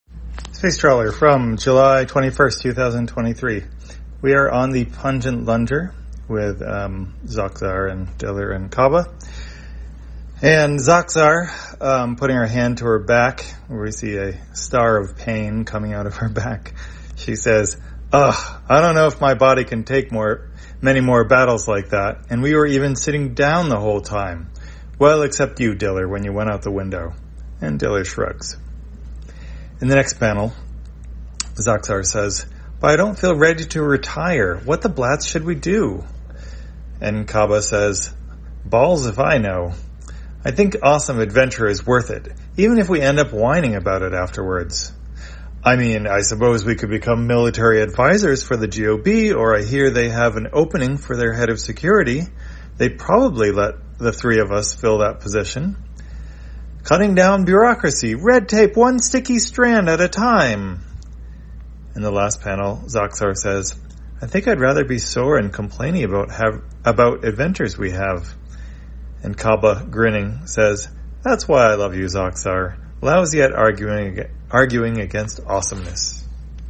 Spacetrawler, audio version For the blind or visually impaired, July 21, 2023.